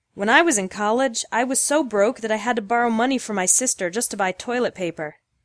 • Listen to the pronunciation